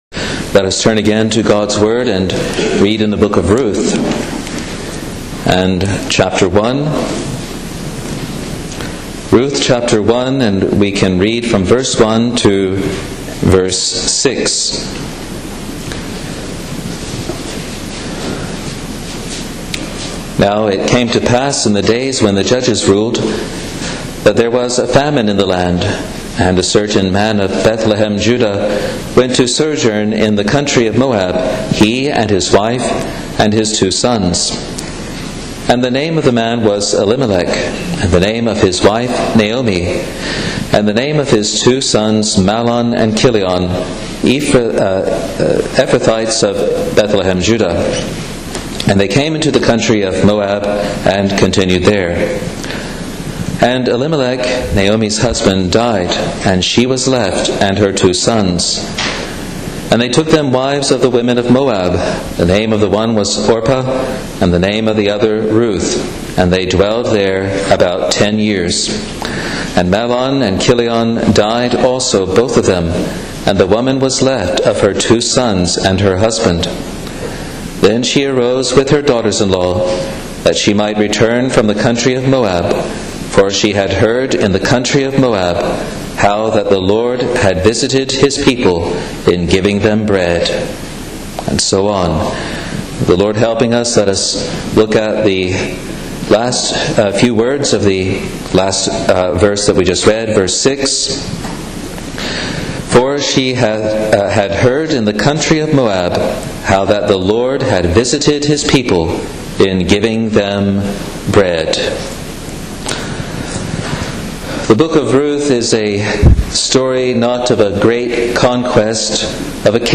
prayer meeting | Free Presbyterian Church of Scotland in New Zealand